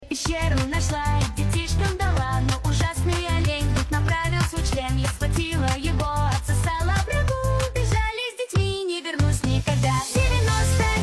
kvinka dala Meme Sound Effect